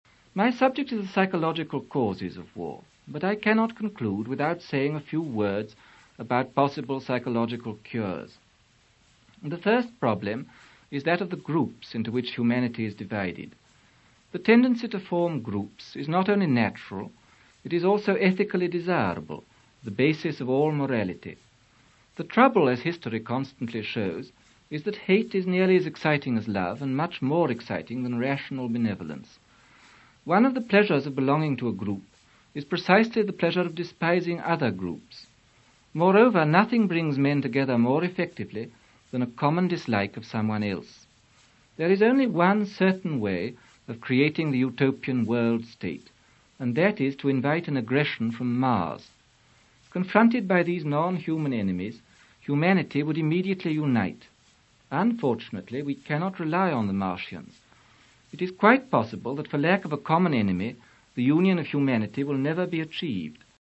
Aldous Huxley's BBC talk,